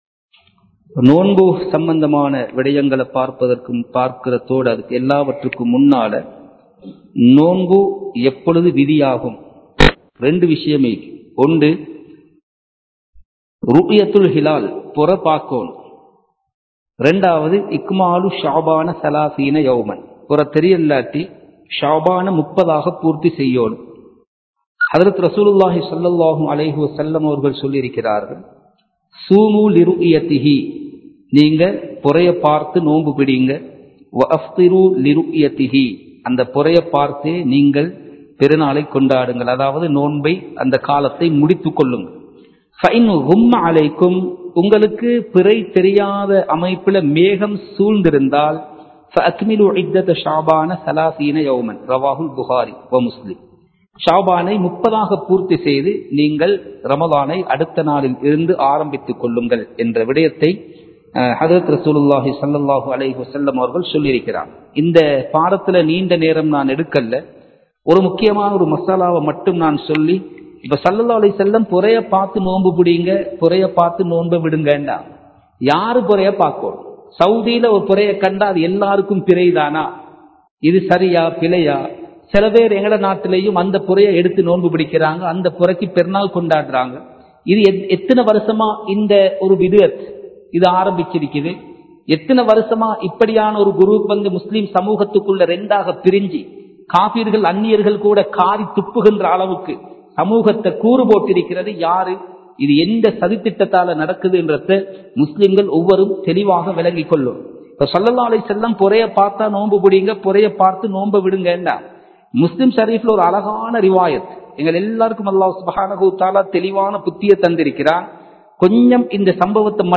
நோன்பின் ஒழுக்கங்கள் | Audio Bayans | All Ceylon Muslim Youth Community | Addalaichenai
Kandy, Kattukela Jumua Masjith